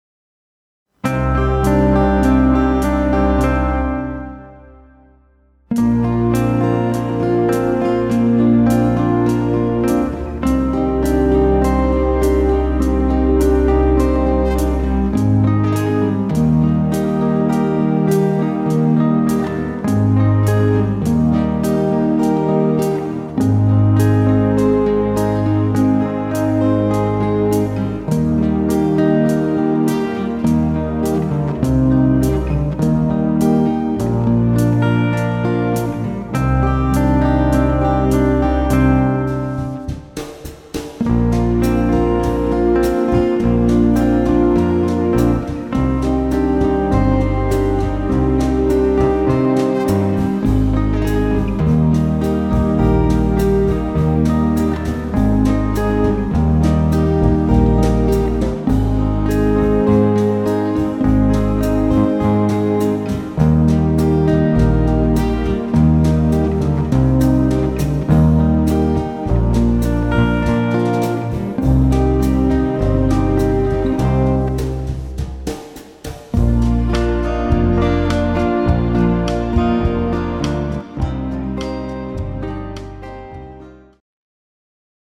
pop ballad  style
tempo 102-104 bpm
male backing track
This backing track is in slow tempo pop folk style.